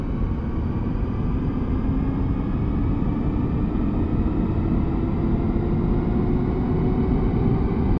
enginestart.wav